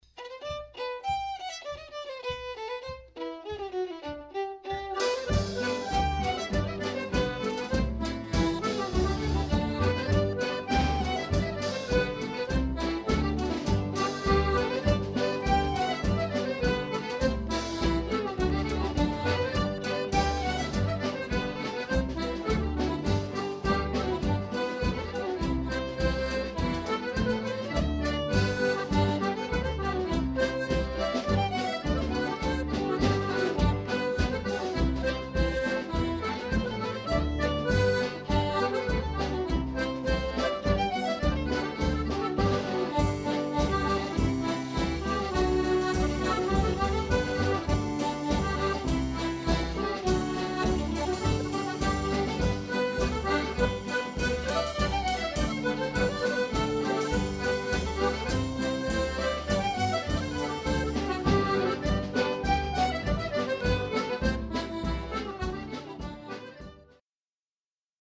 6 x 48 bar polkas